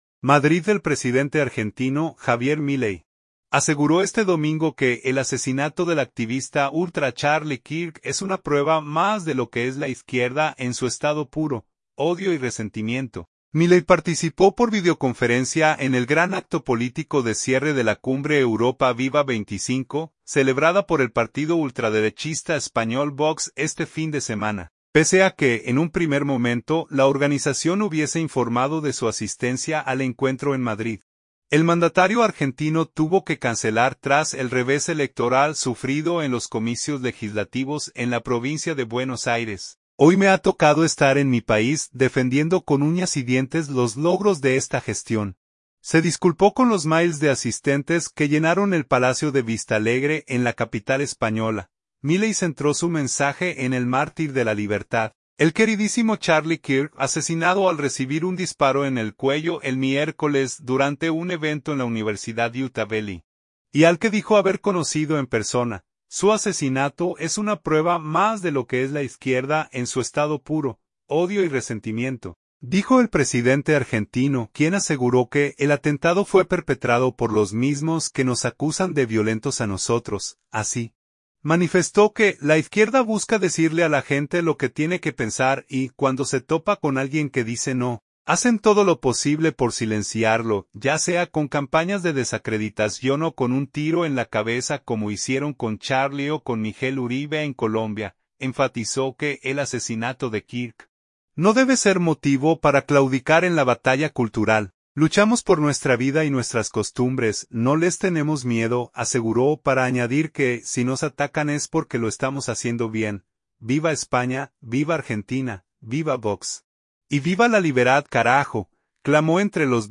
Milei participó por videoconferencia en el gran acto político de cierre de la cumbre 'Europa Viva 25′, celebrada por el partido ultraderechista español Vox este fin de semana.
"¡Viva España!, ¡viva Argentina!, ¡Viva Vox! y ¡Viva la liberad carajo!", clamó entre los vítores del público.